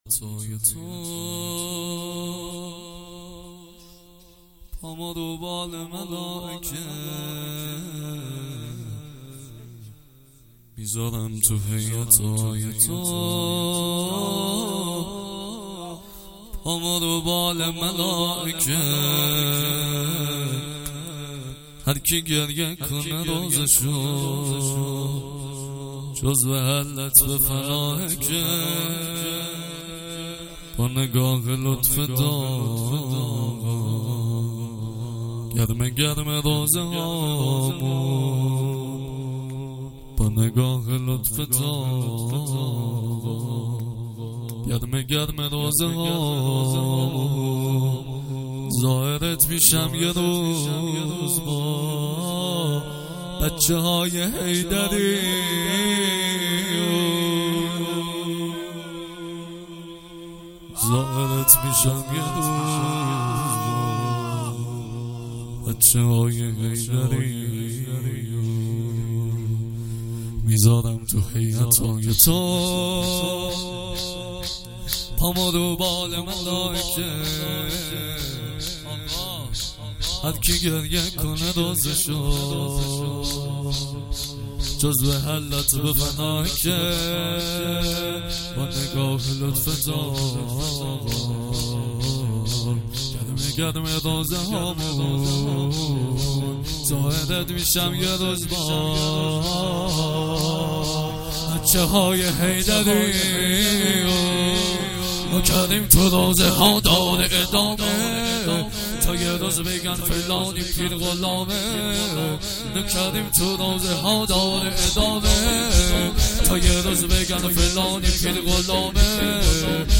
مداحی
هیئت نحن حیدریون